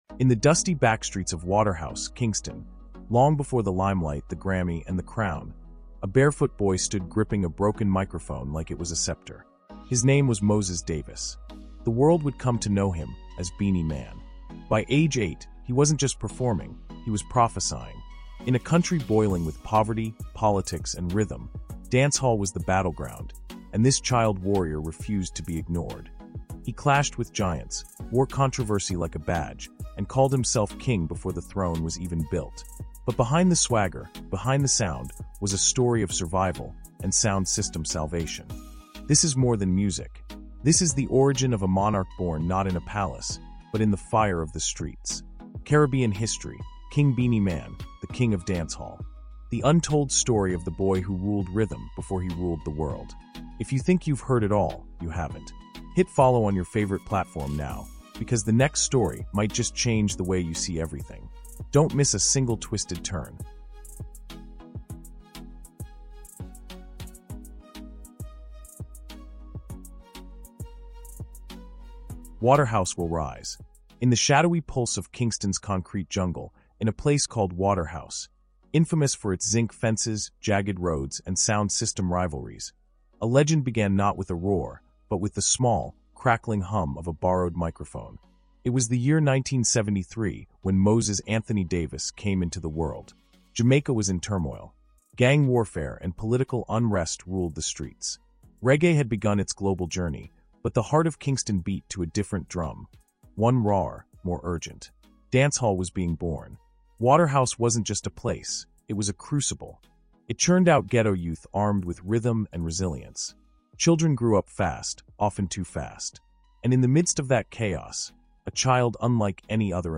With contributions from history experts, rare audio archives, and AI-enhanced narration, this isn’t just the story of one man